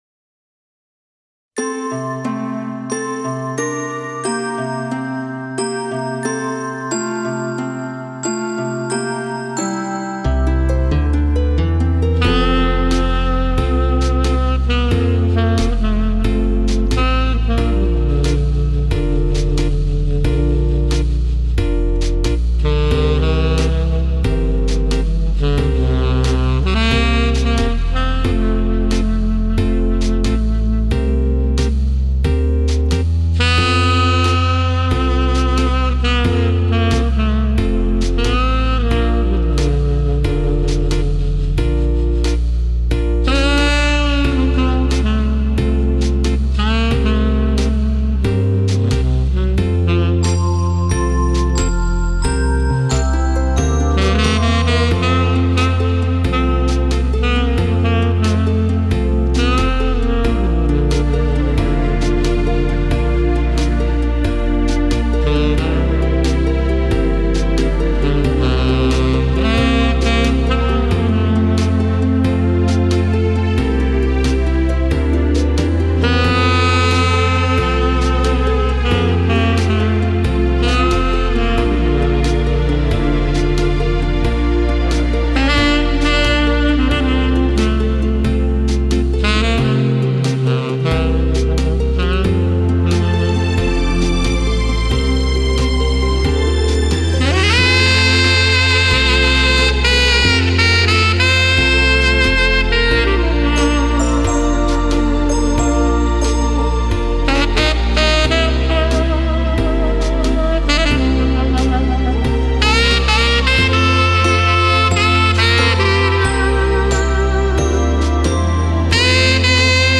Но запись достаточно чистая.